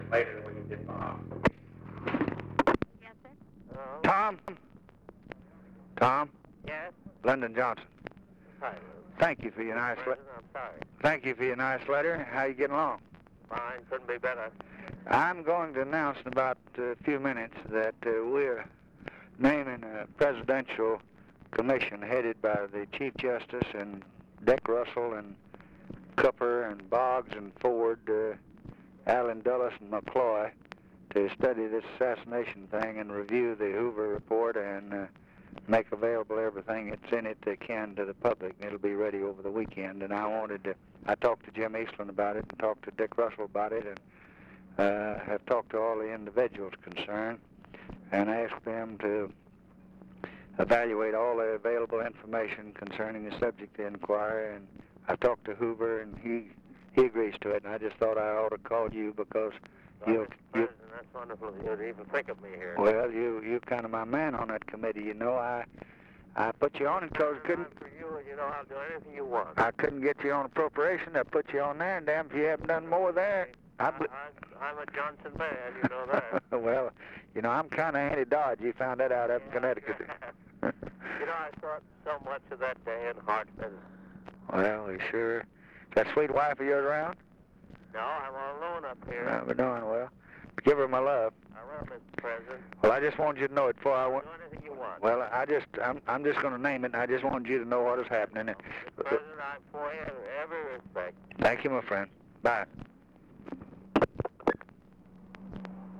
Conversation with THOMAS DODD, November 30, 1963
Secret White House Tapes | Lyndon B. Johnson Presidency Conversation with THOMAS DODD, November 30, 1963 Rewind 10 seconds Play/Pause Fast-forward 10 seconds 0:00 Download audio Previous Conversation with WILLIAM MCC.